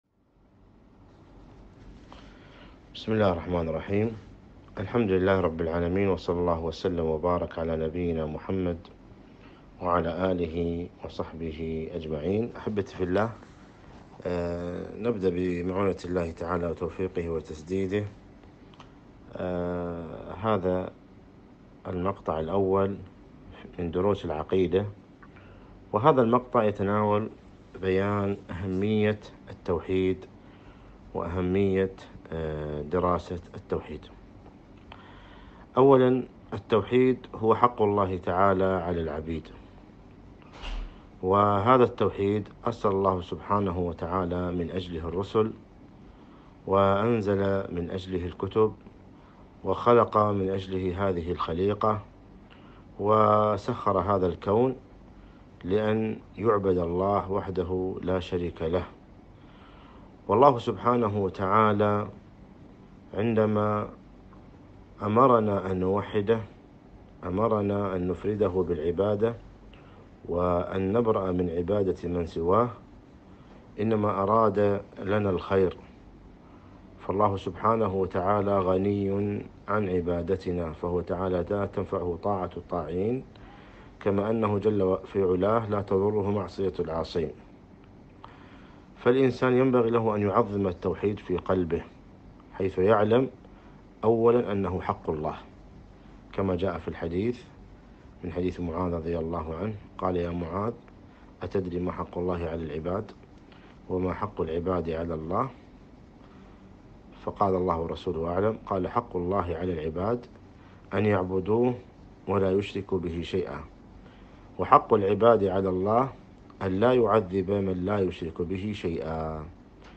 محاضرة - أهمية التوحيد